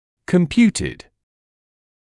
[kəm’pjuːtɪd][кэм’пйуːтид]вычисленный; рассчитанный; 2-я и 3-я форма от to compute